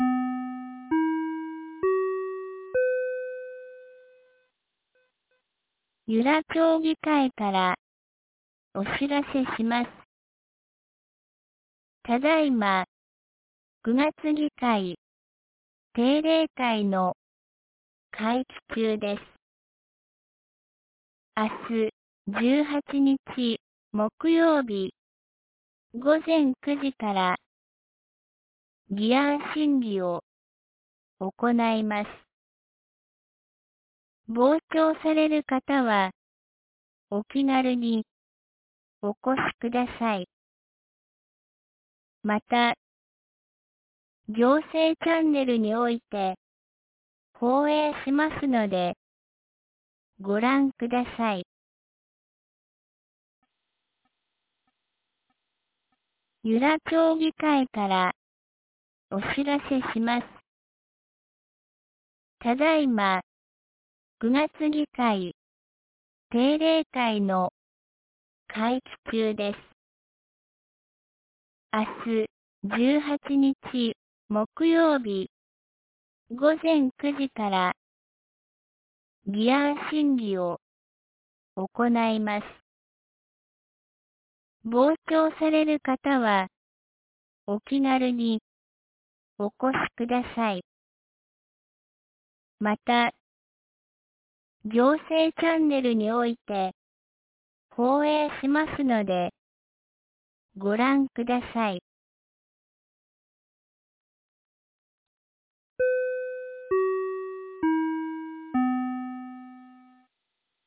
2025年09月17日 17時12分に、由良町から全地区へ放送がありました。